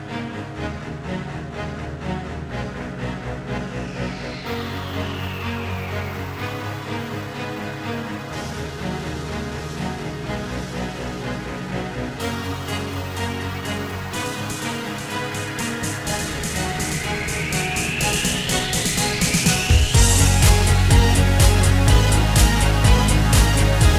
Two Semitones Down Pop (2000s) 3:47 Buy £1.50